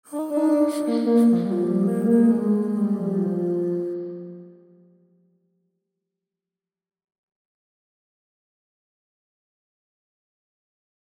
Spring Reverb — Federhall
Dieser Reverb-Typ hat einen charakteristischen, leicht metallischen Klang und wird häufig in Gitarrenverstärkern verwendet. Im folgenden Soundbeispiel hören Sie einen Gesang mit einem Federhall-Effekt:
vocals-spring-reverb.mp3